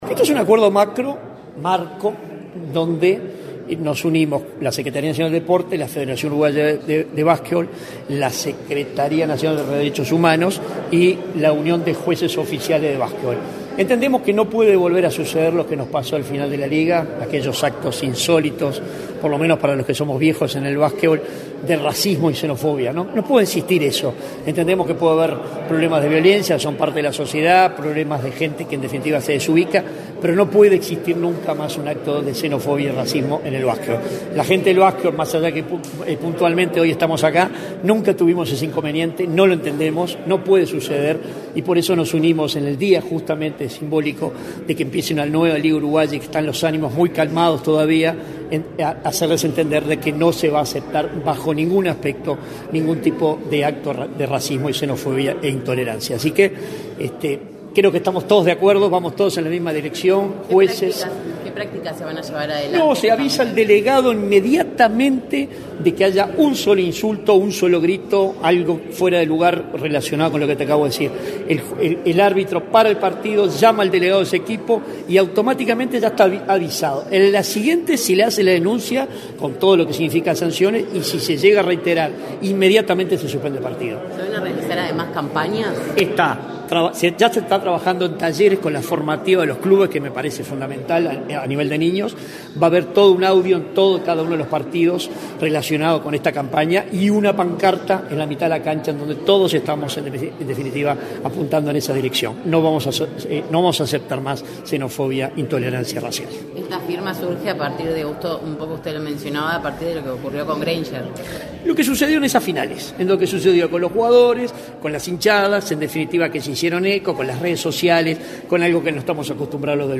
Declaraciones de prensa del subsecretario nacional del Deporte, Enrique Belo